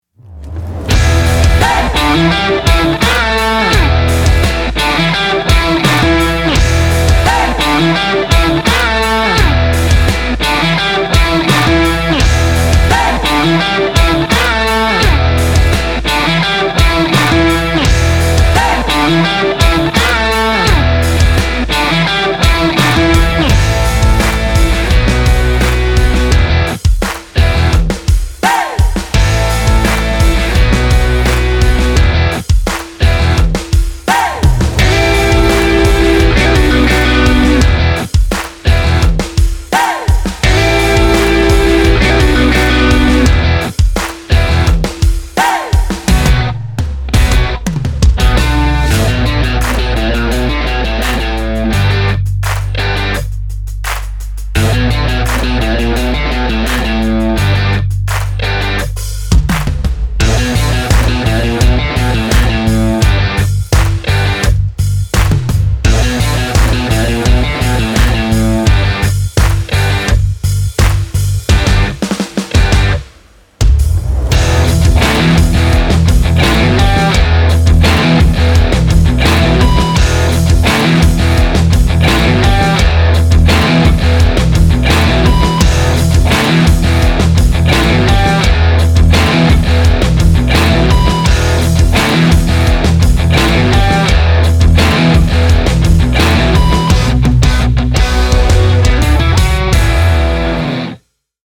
高揚感のある熱いロック
ロック 1:41 ダウンロード